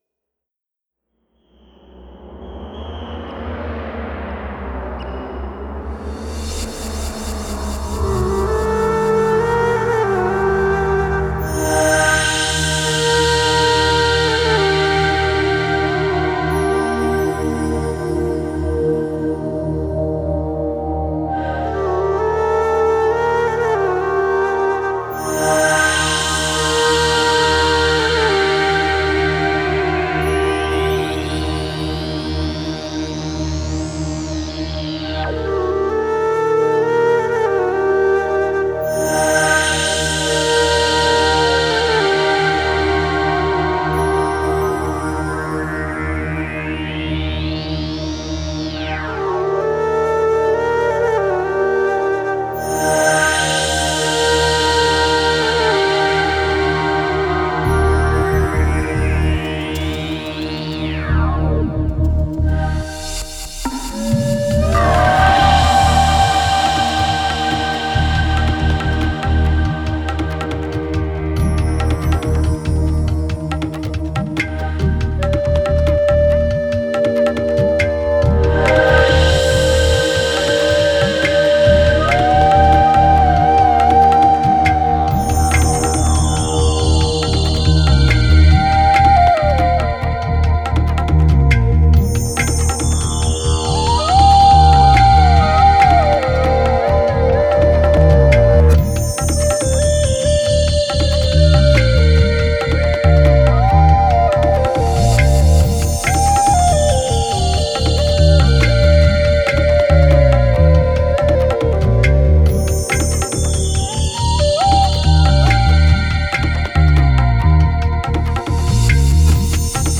Genre: Lounge, Downtempo.